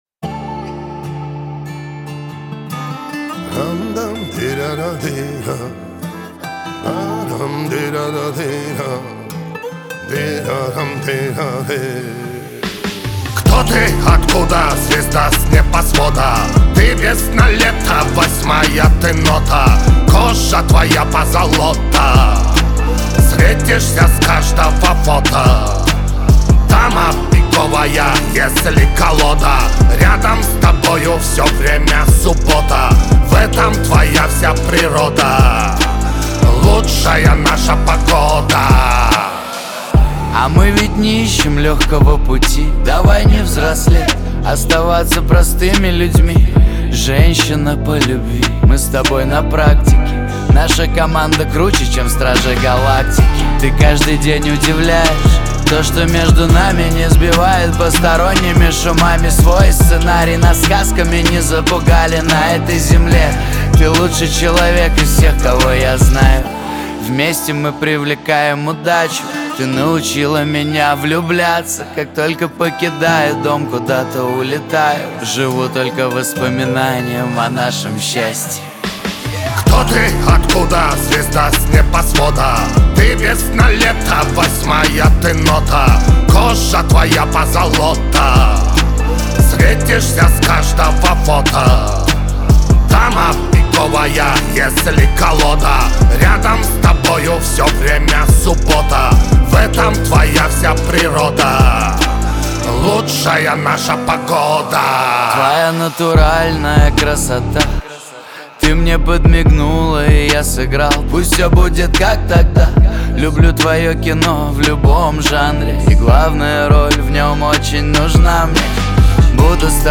Жанр: Hip-Hop/Rap